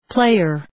Προφορά
{‘pleıər}